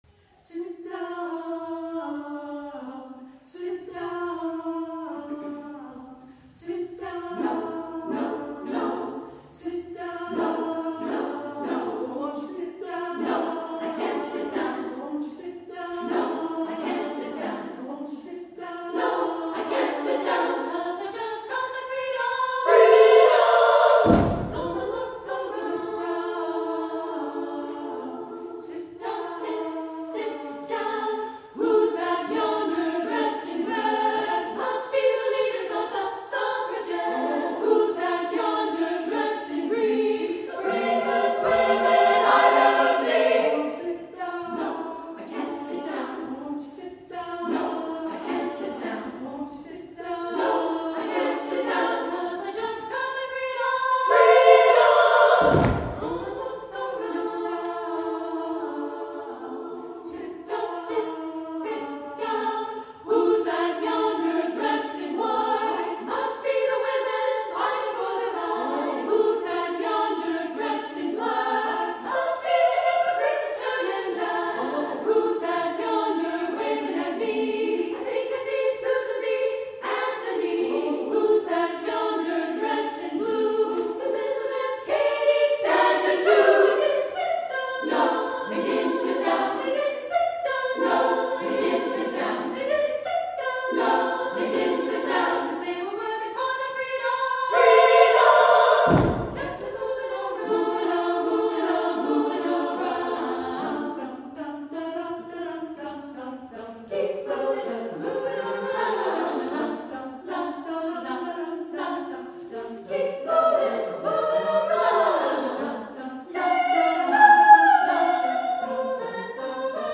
for SSAA Chorus (2000-02)
The Spirit of Women is a set of three songs for Women's Chorus, a cappella.
The musical alterations to the original song include "blues" harmonies and hand-claps (for joy!).